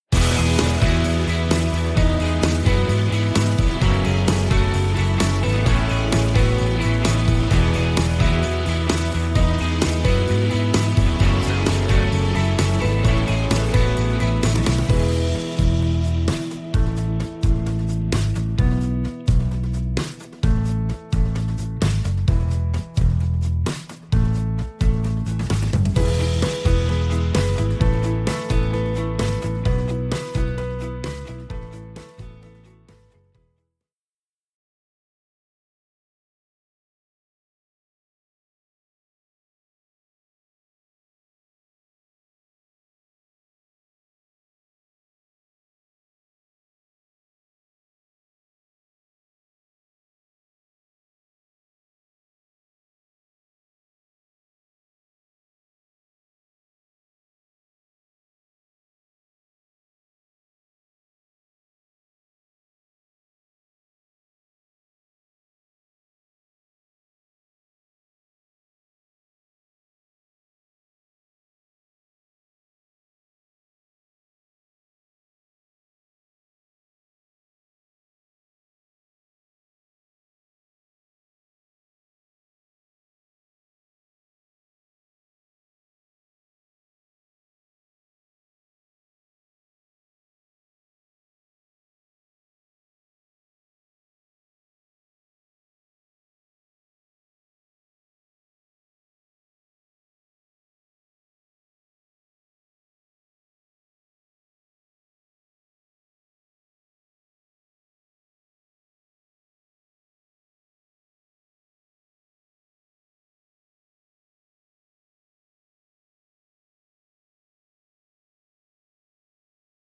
Genre: insight and spirituality